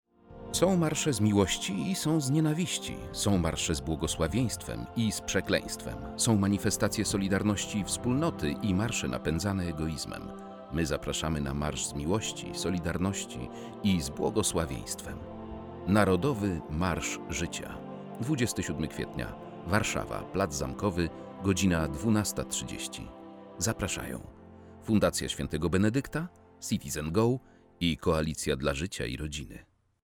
Narodowy Marsz Życia 2025 – spot 1